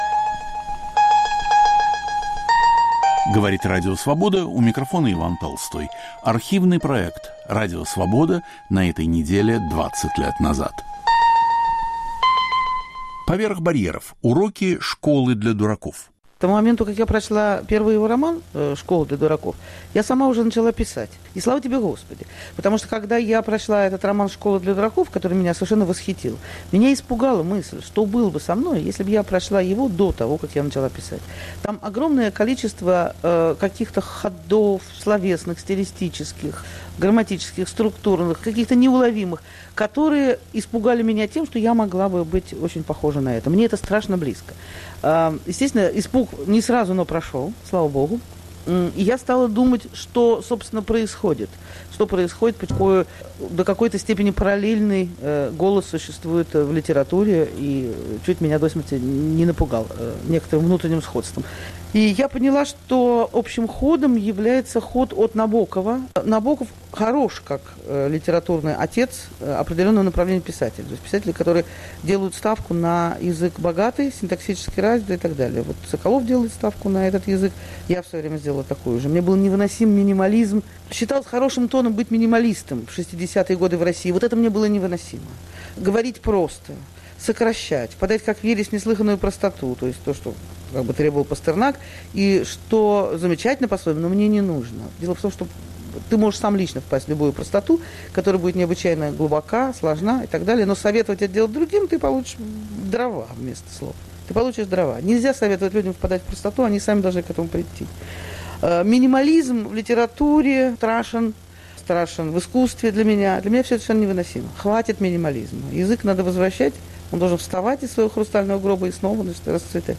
Передача Александра Гениса к 60-летию Саши Соколова.